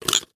InsertBattery_1.wav